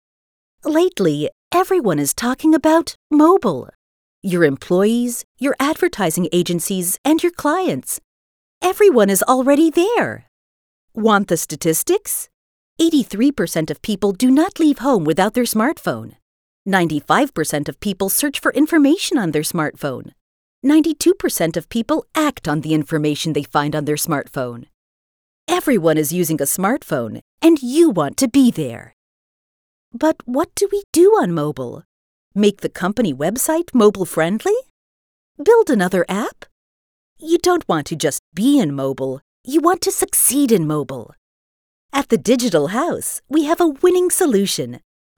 Anglais (américain)
Chaleureux
De la conversation
Professionnel